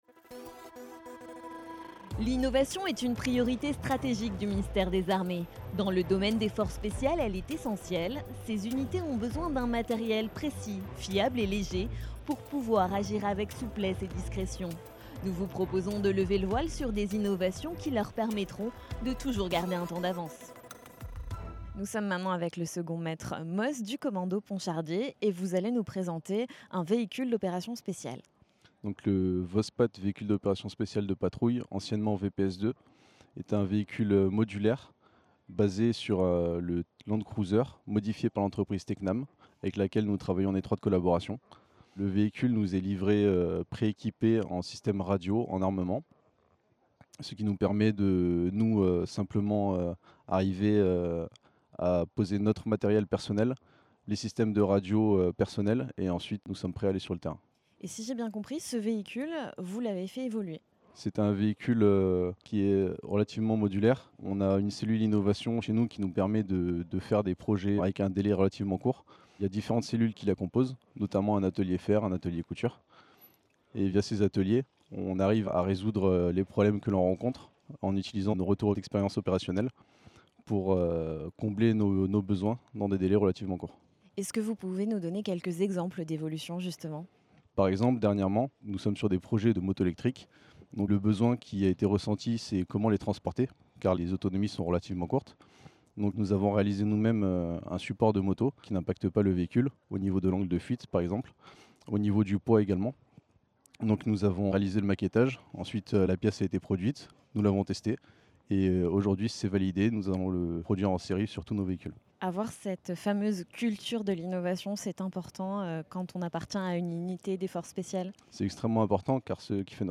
A l’occasion de la Journée mondiale de la créativité et de l’innovation, la rédaction vous propose, pendant deux jours, d’écouter des innovateurs civils et militaires qui ont mobilisé leurs idées et talents au service des forces spéciales.